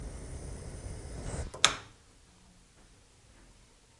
Flashlight Clicking
描述：Sounds of a flashlight clicking on and off. Fast and slow.
标签： OWI turn off flashlight clicking
声道立体声